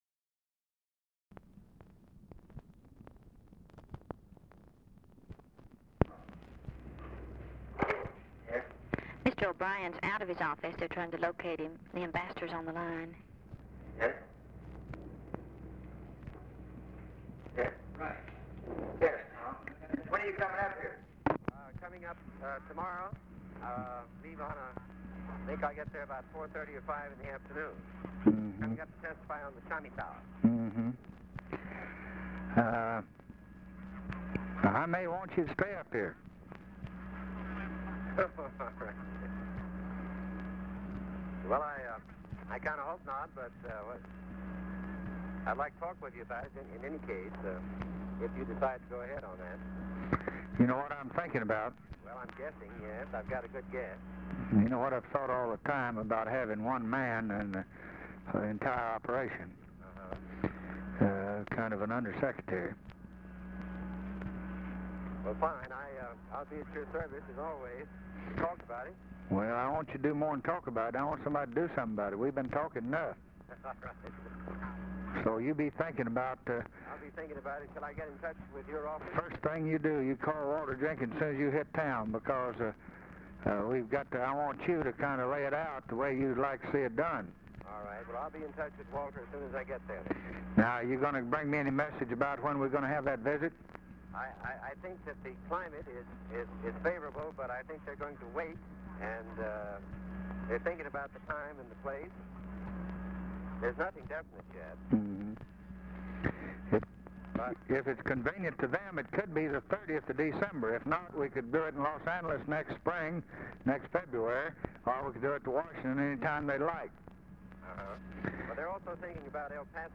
Conversation with THOMAS MANN, December 9, 1963
Secret White House Tapes